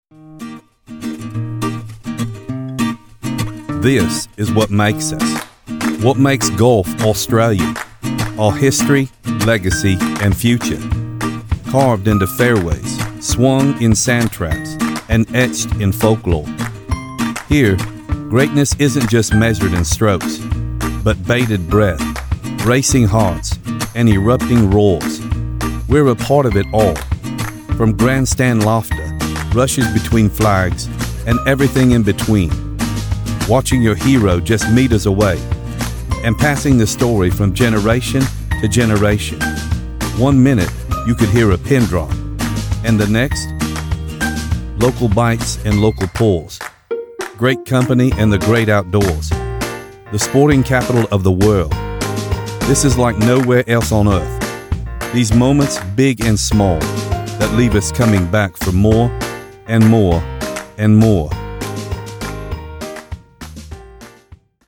Has Own Studio
australian | character
COMMERCIAL 💸
Austrailian_Golf_Promo.MP3